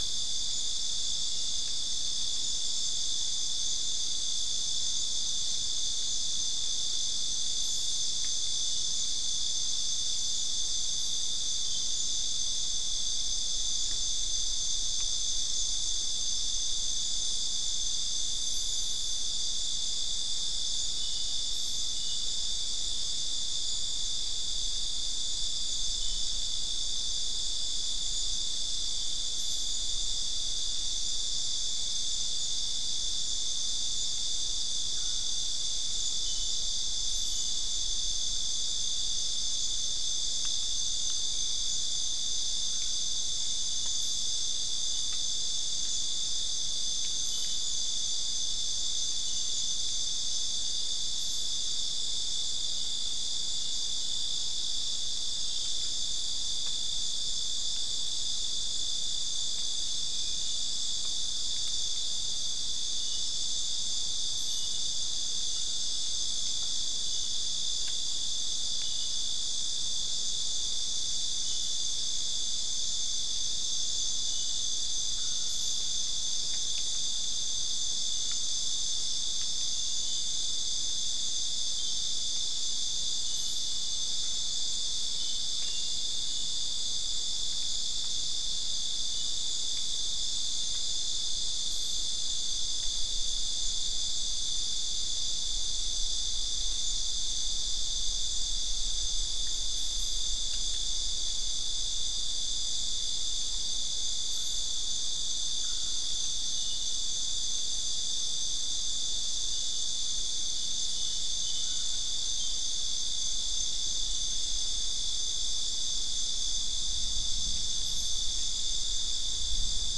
Non-specimen recording: Soundscape Recording Location: South America: Guyana: Sandstone: 4
Recorder: SM3